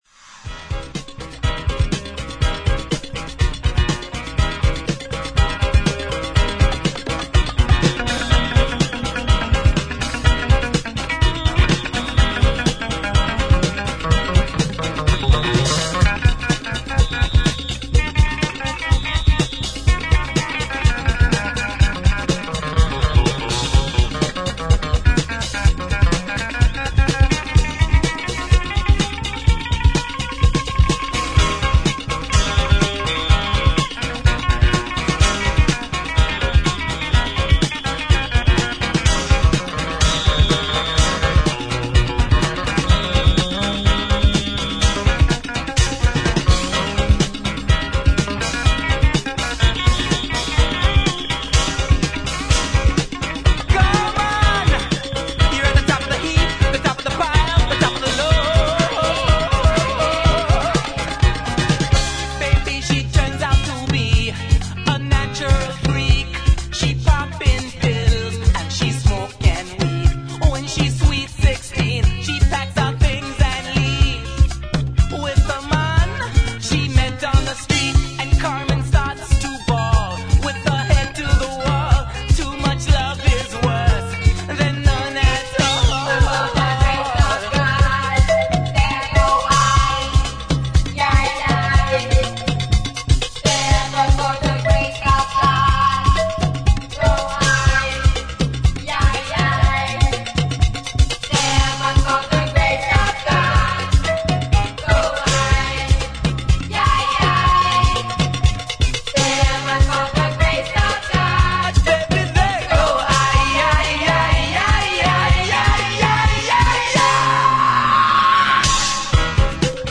軽快なニューウェーヴ・サウンドにファニーな女性ヴォーカルをフィーチャーした